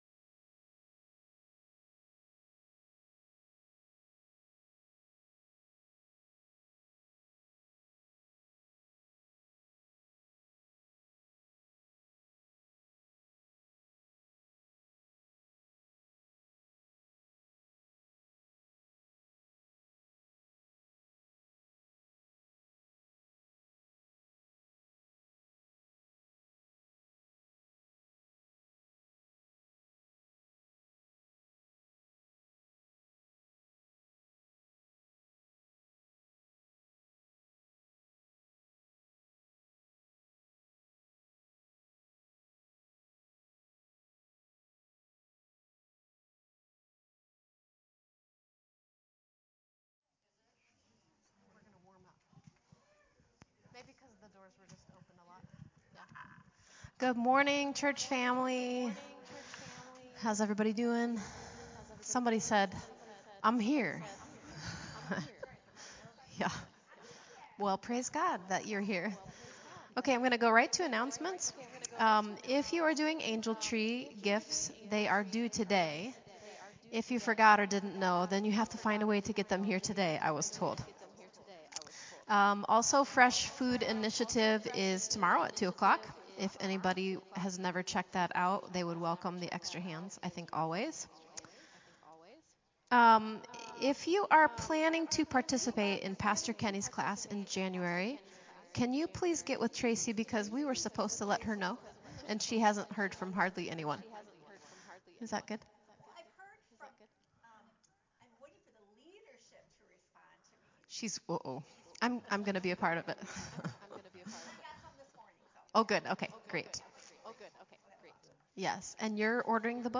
the team bring us some more great Christmas songs.
Praise Worship
Advent Reading and Prayer